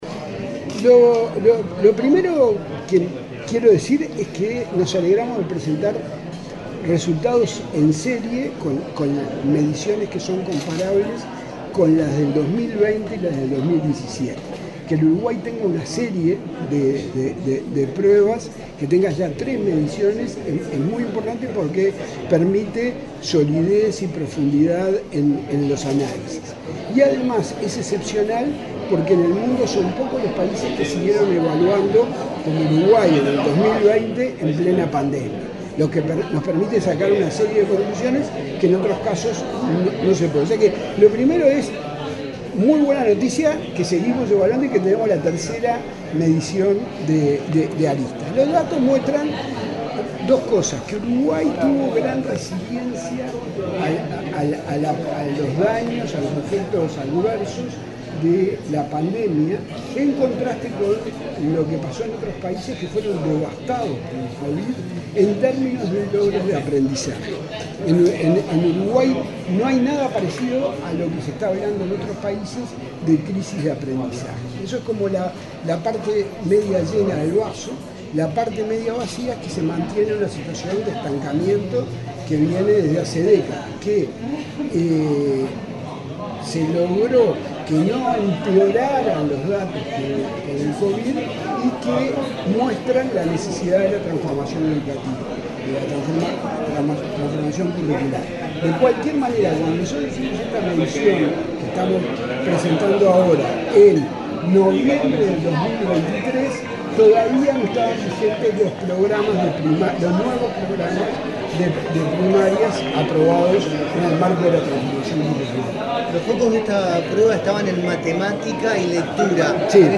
Declaraciones del presidente de la comisión directiva del Ineed
Declaraciones del presidente de la comisión directiva del Ineed 24/02/2025 Compartir Facebook X Copiar enlace WhatsApp LinkedIn Este lunes 24 en Montevideo, el presidente de la comisión directiva del Instituto Nacional de Evaluación Educativa (Ineed), Javier Lasida, dialogó con la prensa, antes de encabezar la presentación de los resultados de la última aplicación de Aristas Primaria.